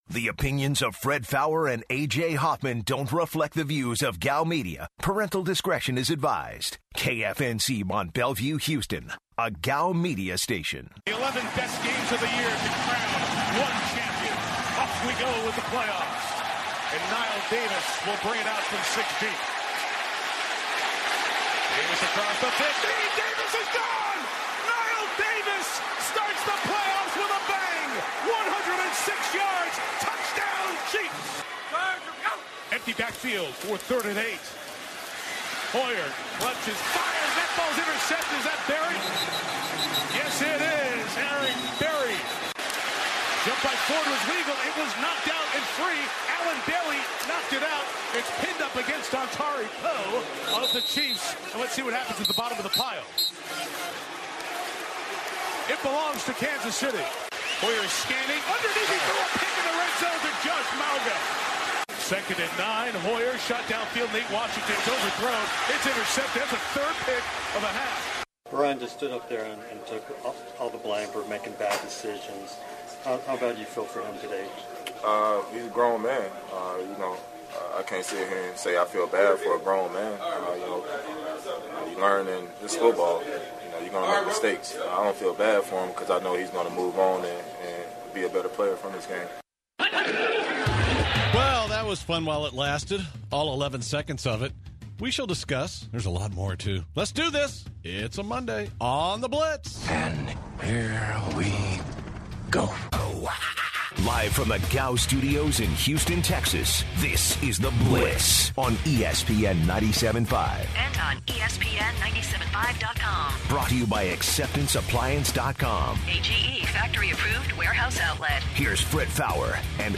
The guys interviewed Stanford Routt and they talked more about the Texans and Brian Hoyer.